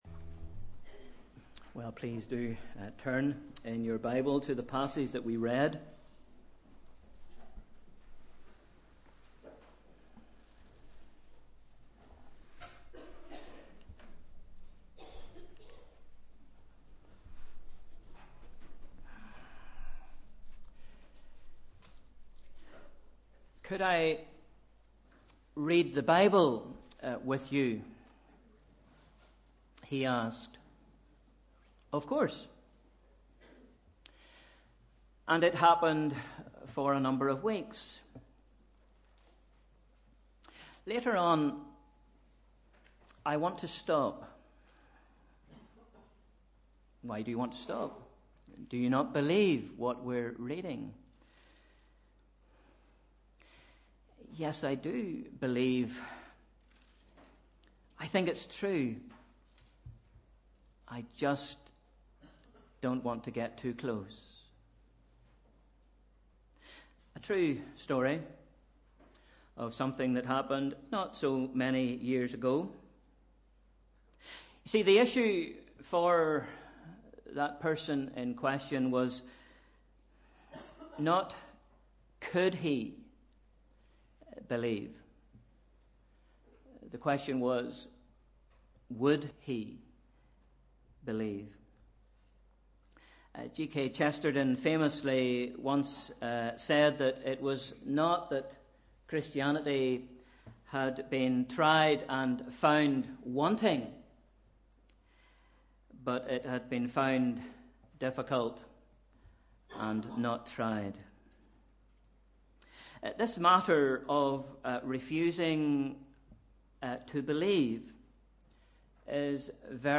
Sunday 2nd April 2017 – Morning Service